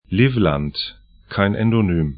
Livland 'li:flant Vidzeme 'vi:tzɛmə lv Gebiet / region 57°30'N, 26°00'E